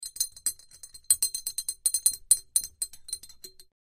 BeakerStirGlassCU PE266003
Beaker; Stir 3; A Glass Stirrer Briskly Stirring In A Glass Beaker; Close Perspective. Pharmacy, Lab.